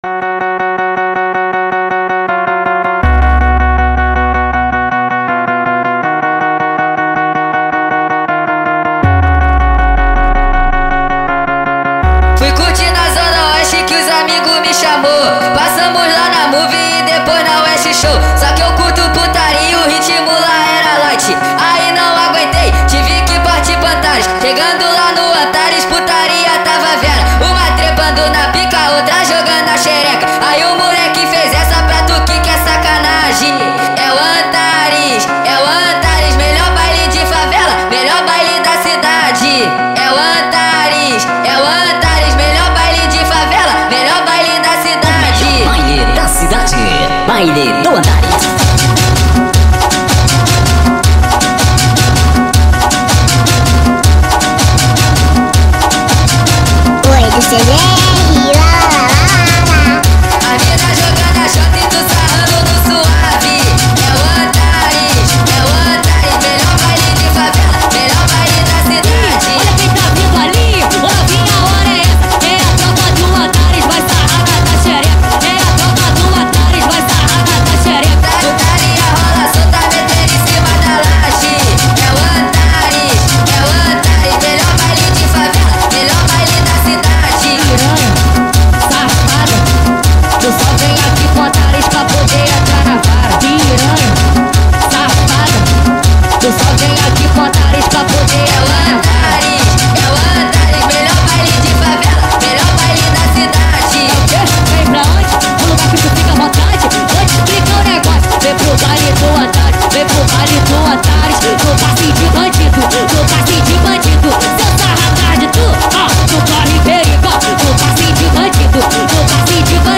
2024-07-17 12:32:55 Gênero: Funk Views